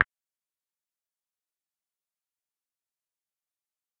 footstep.ogg